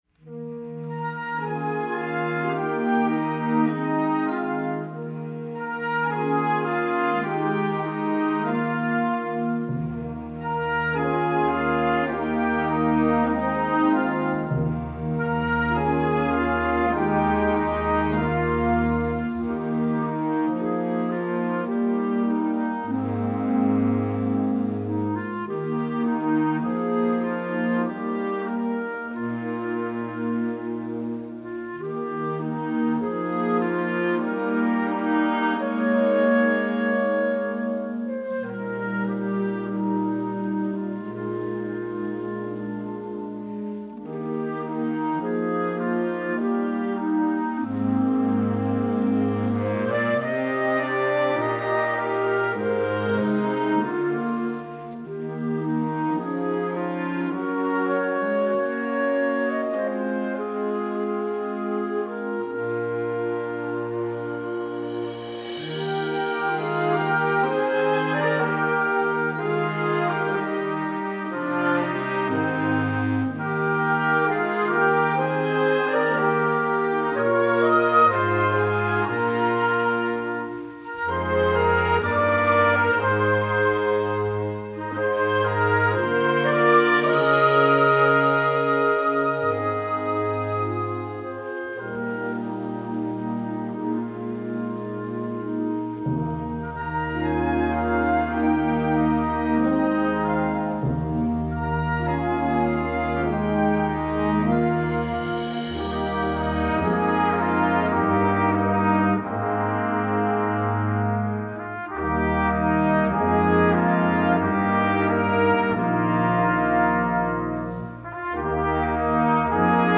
Gattung: Jugendwerk
Besetzung: Blasorchester
Die wunderschöne Ballade